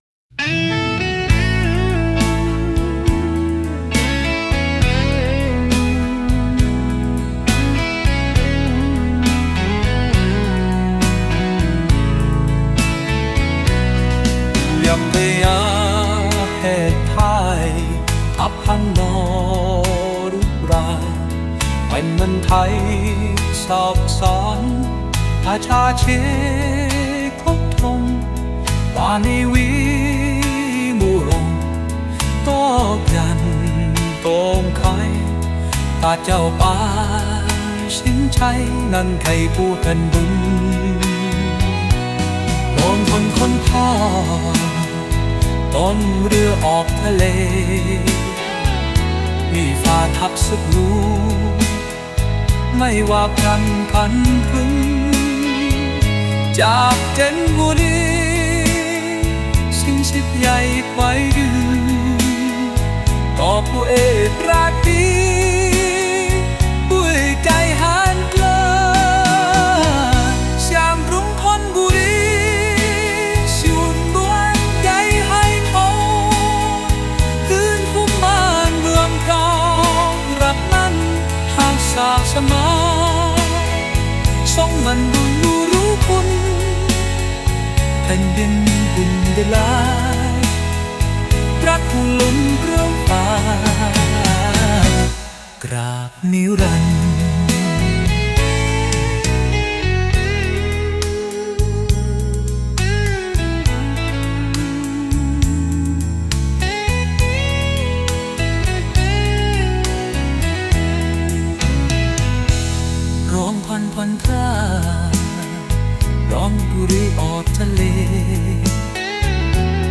ผลงานอาจารย์ สื่อการสอนโดยAI เพลงประวัติพระเจ้าตากสิน ประวัติศาสตร์กรุงธนบุรี